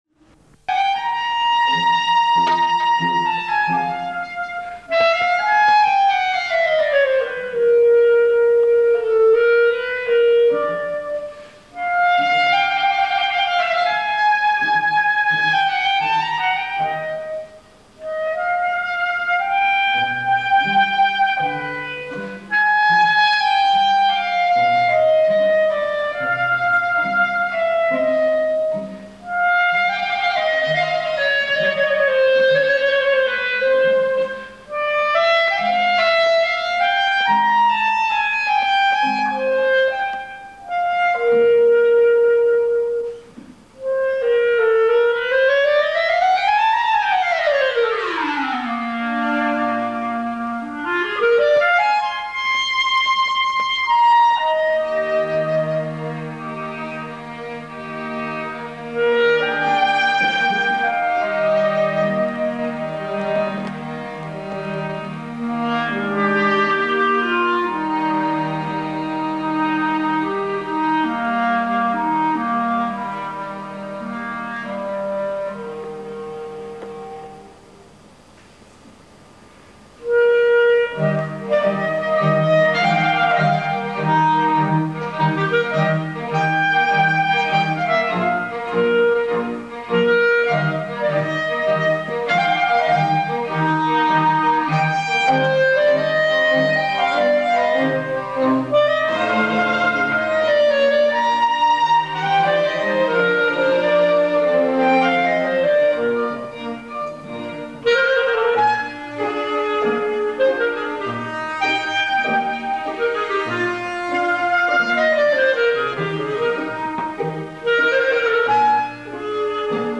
The tracks contained in these tracks were recorded during my long clarinet activity in many Italian concert halls. Of course, the quality of the recordings, is not the best, because, made live without my knowledge by some of my friends or some of my students in the room.
Clarinet
String Orchestra “T. Schipa” (Lecce)